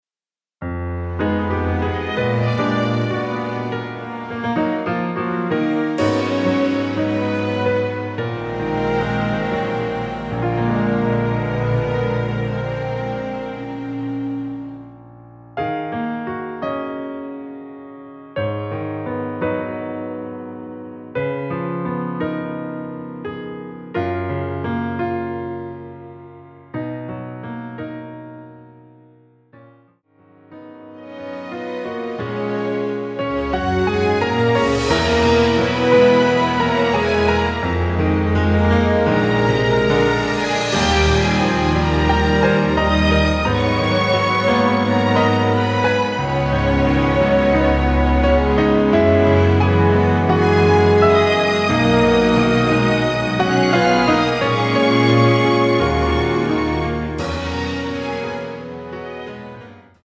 Ebm
◈ 곡명 옆 (-1)은 반음 내림, (+1)은 반음 올림 입니다.
앞부분30초, 뒷부분30초씩 편집해서 올려 드리고 있습니다.
중간에 음이 끈어지고 다시 나오는 이유는